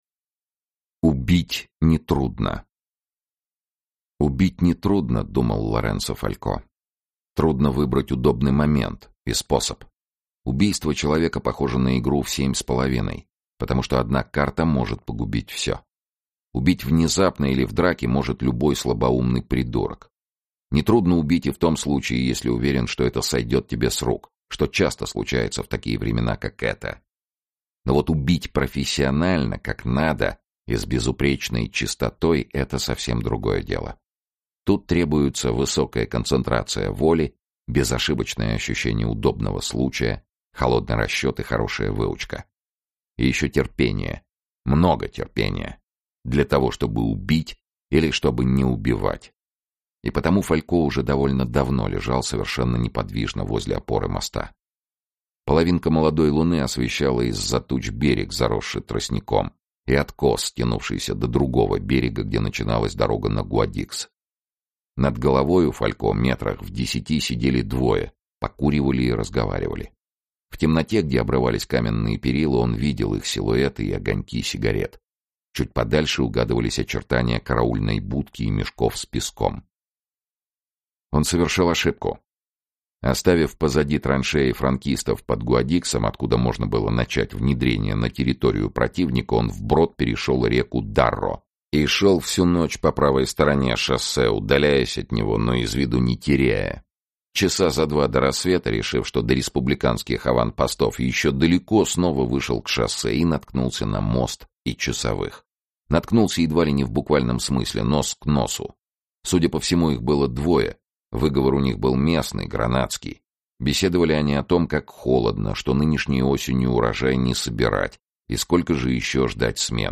Фалько Аудиокнига